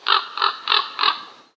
Звуки птиц. Sounds of birds.
Звук карканье вороны.